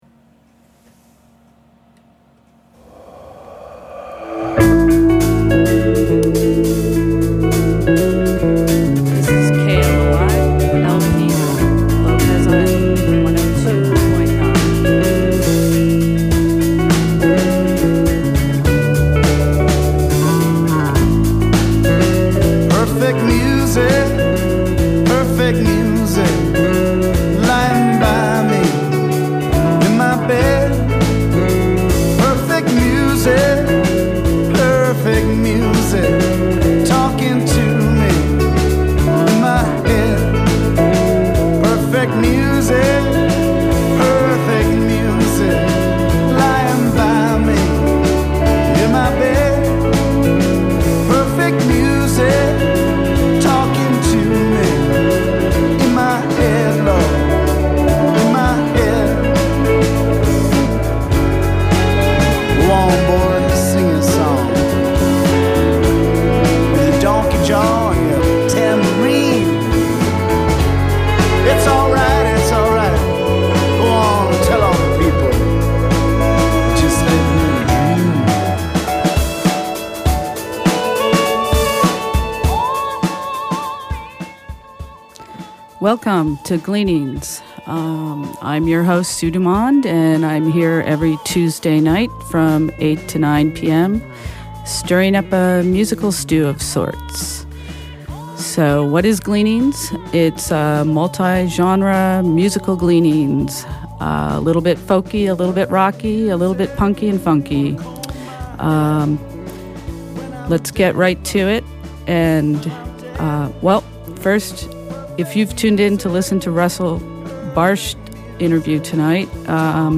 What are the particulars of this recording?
I recorded this from my home computer from the internet stream using a radio recorder . There are some small bumps in the recording due to the bumps in the stream during some re-buffers.